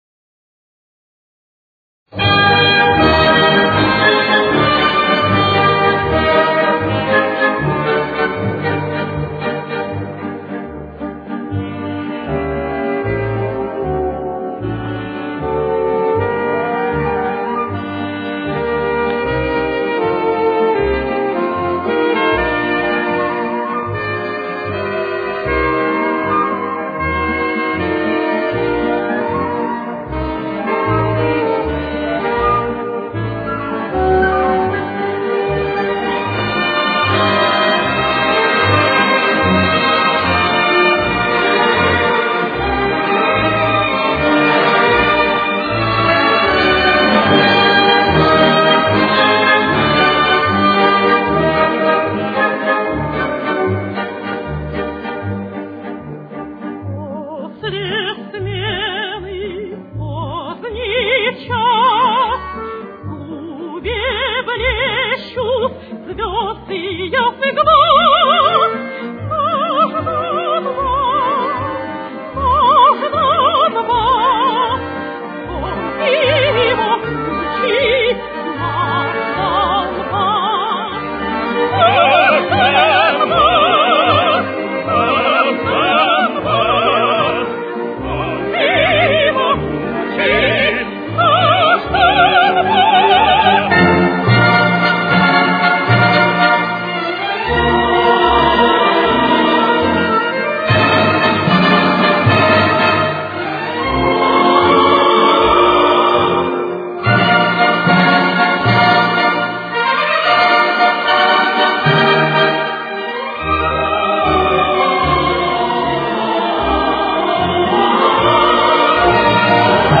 Соль минор. Темп: 232.